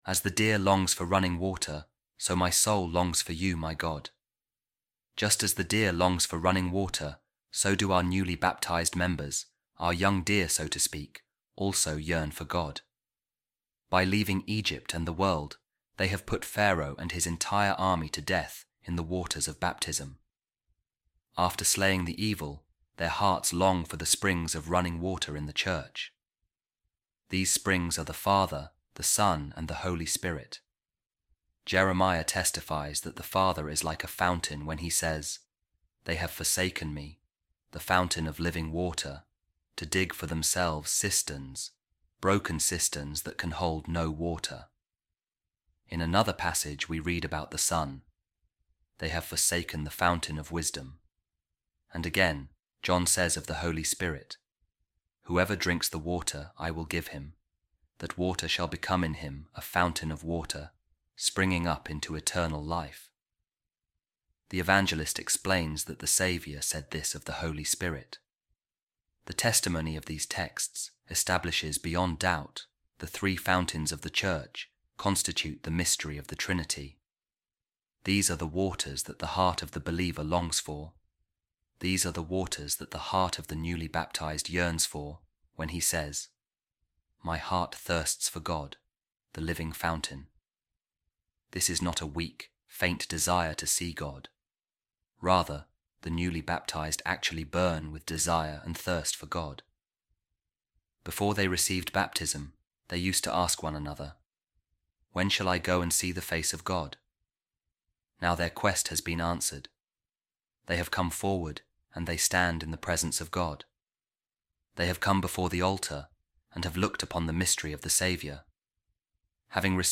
Office Of Readings | Week 13, Thursday, Ordinary Time | A Reading From A Homily Of Saint Jerome On The Newly Baptized